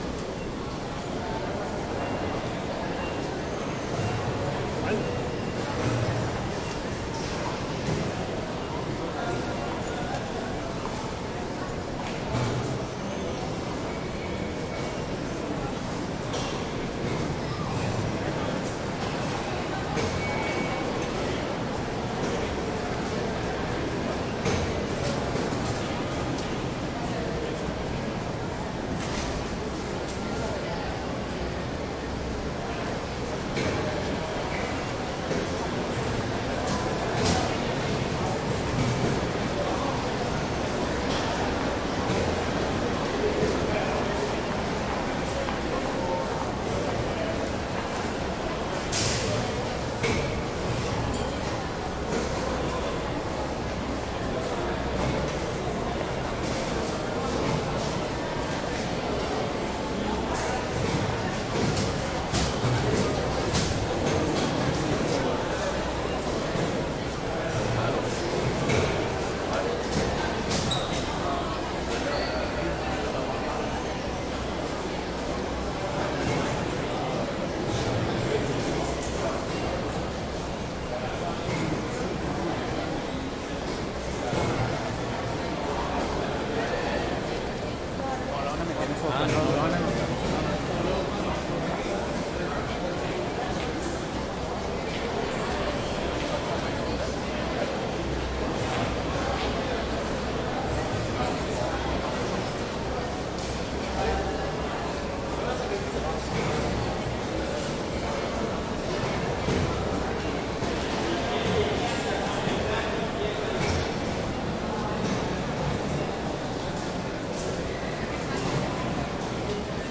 random " 地铁int ride 5 stations quebecois voices 蒙特利尔，加拿大
描述：地铁地铁int乘坐5站魁北克声音蒙特利尔，Canada.flac
标签： 蒙特利尔 魁北克 地铁 5 人声鼎沸 INT 车站 地铁 加拿大
声道立体声